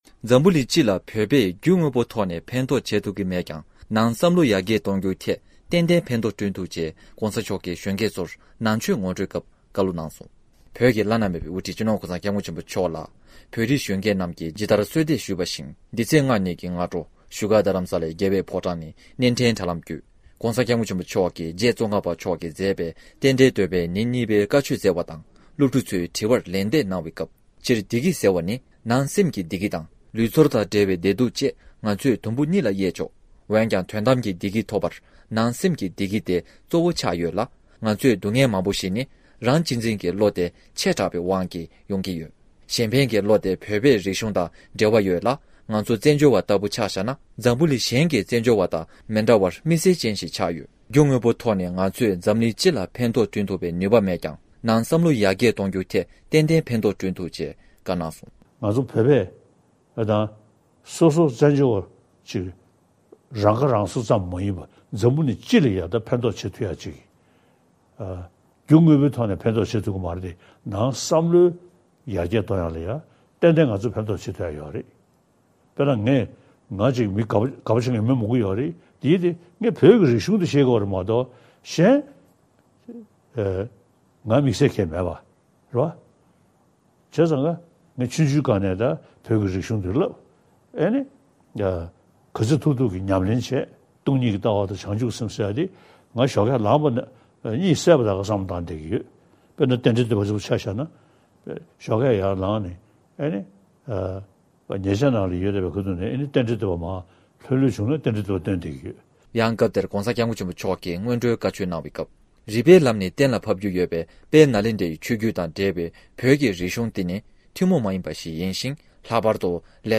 ས་གནས་ནས་བཏང་བའི་གནས་ཚུལ།
སྒྲ་ལྡན་གསར་འགྱུར།